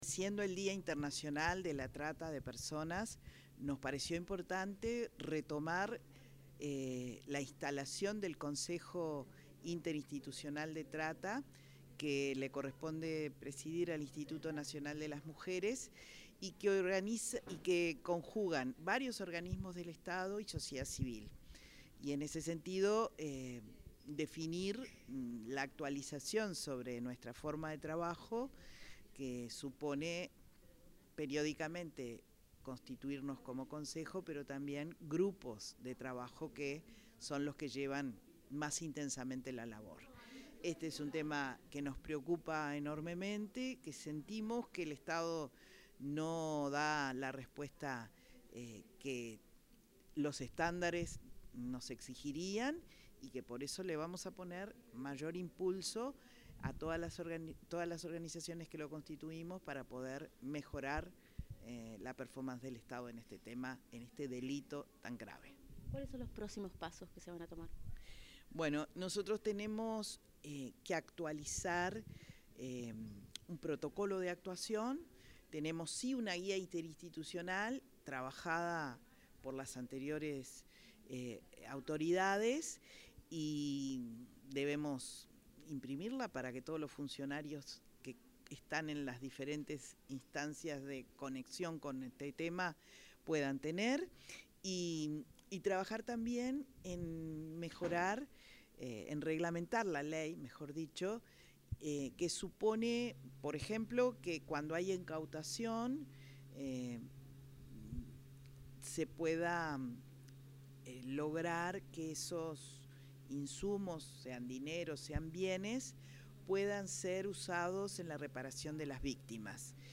Declaraciones de la directora de Inmujeres, Mónica Xavier
Declaraciones de la directora de Inmujeres, Mónica Xavier 30/07/2025 Compartir Facebook X Copiar enlace WhatsApp LinkedIn Luego de la primera sesión del Consejo Nacional de Prevención y Combate a la Trata y la Explotación de Personas en este período, la directora del Instituto Nacional de las Mujeres (Inmujeres), Mónica Xavier, realizó declaraciones a la prensa.